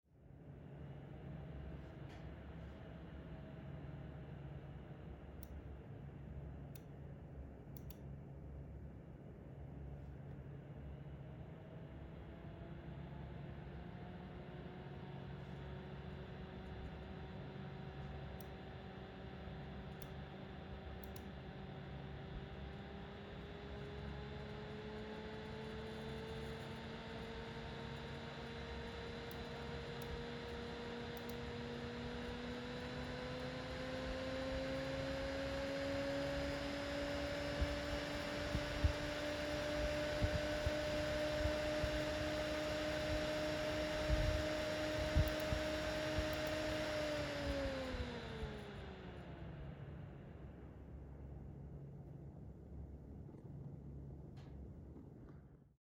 Dabei ist mir ein unangenehmes Summen/Surren des Lüfters aufgefallen. Es ist definitiv nicht das Resonanzbrummen, was auch im CB Test angemerkt wurde, da es definitiv vom vorderen Lüfter kommt und relativ hoch vom Ton her ist. Mit dem Afterburner habe ich beide Lüfter unabhängig getestet, während der hintere selbst bei 90% nur stark rauscht, tritt bei dem vorderen bereits bei ca. 35% das Surren auf, ab ca. 50% wird es schon unagenehm.
Audiodatei anbei wurde mit einem iPhone aus ca. 10cm Entfernung aufgenommen, Geschwindigkeit zunächst 35%, über 60 bis hin zu knapp 90% Drehzahl.